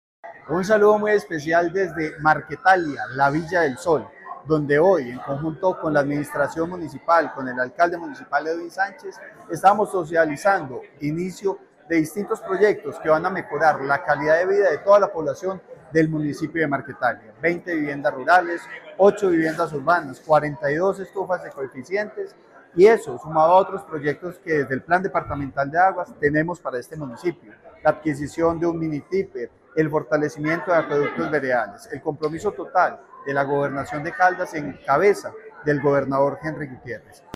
Francisco Javier Vélez Quiroga, secretario de Vivienda y Territorio